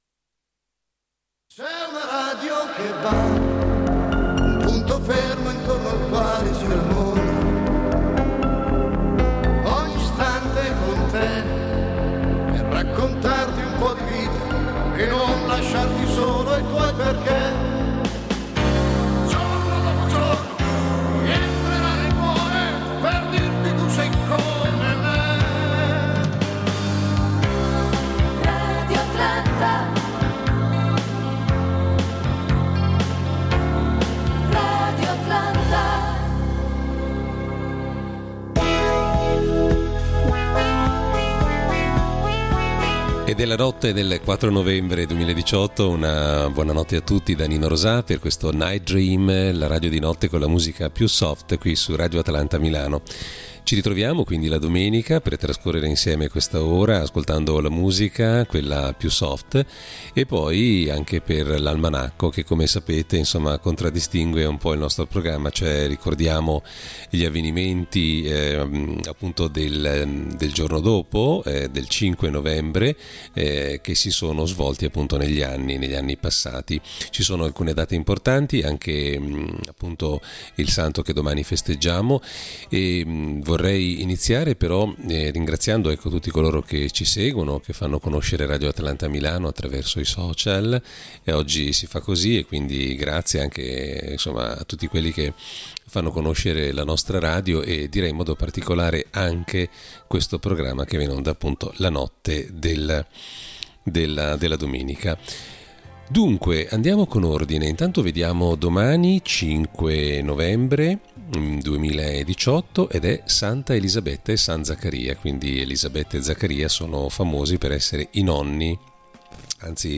La radio di notte con la musica più soft. All’interno del programma: l’almanacco del giorno dopo, la musica country e le serie televisive degli anni 70/80.